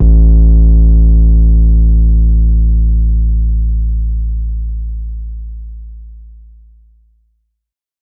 808 Just Blaze Long.wav